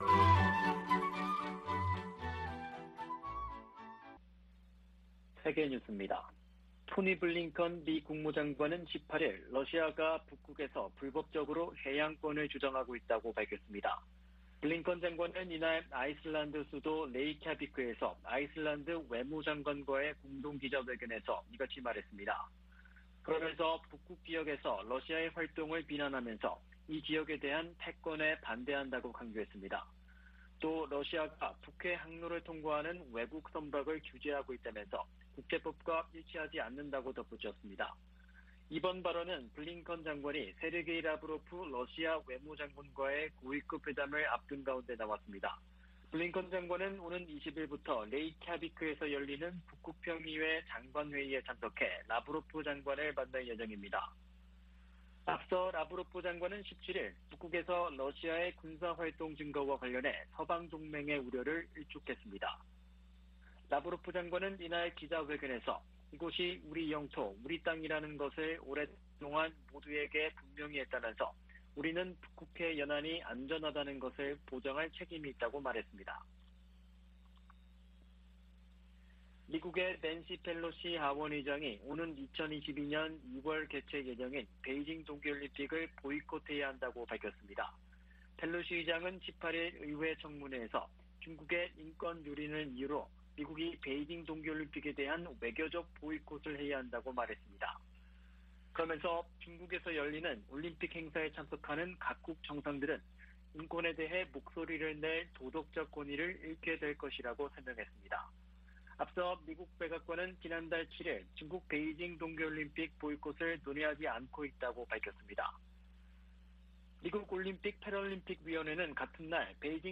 VOA 한국어 아침 뉴스 프로그램 '워싱턴 뉴스 광장'입니다.